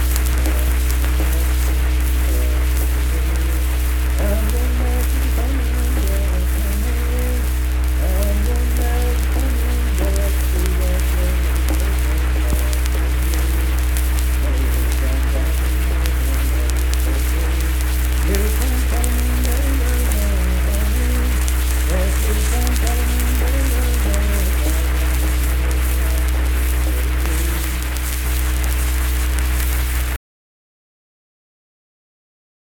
Unaccompanied vocal music
Performed in Kanawha Head, Upshur County, WV.
Dance, Game, and Party Songs
Voice (sung)